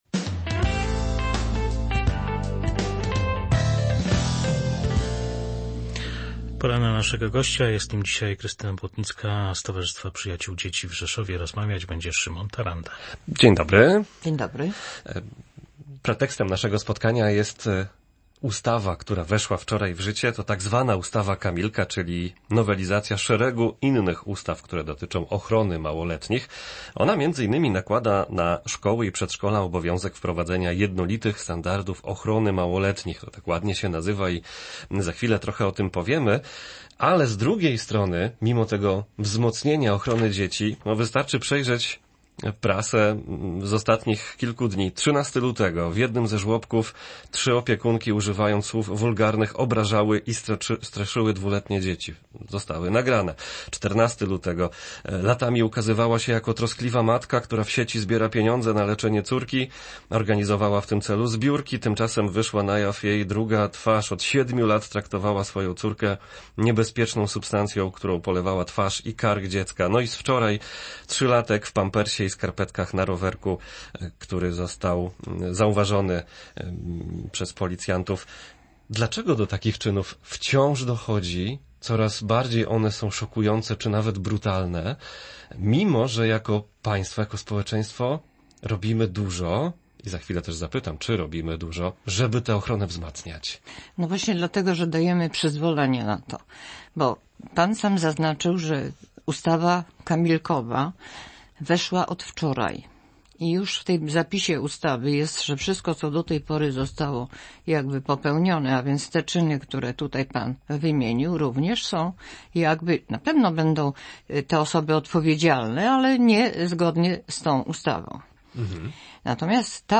Więcej na ten temat w rozmowie